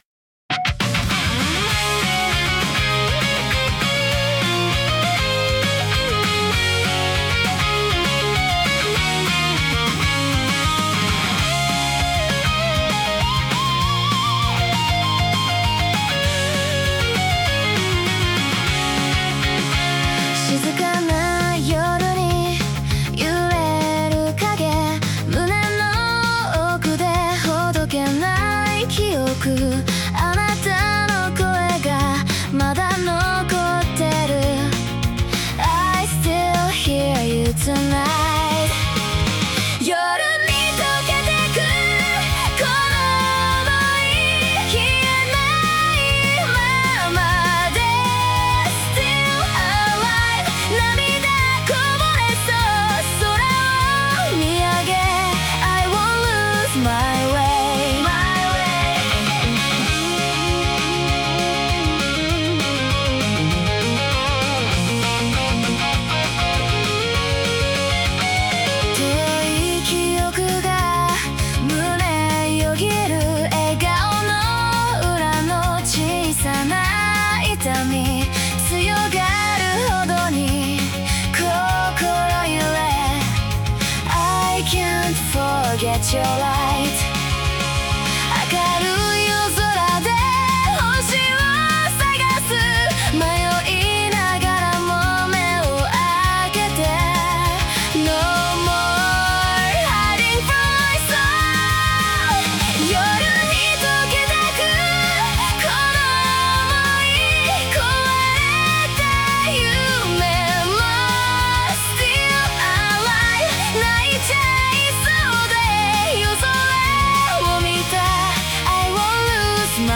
女性ボーカル
イメージ：90年代J-ROCKバンド,90年代ポップロック,パンクロック,アンセム,女性ボーカル,切ない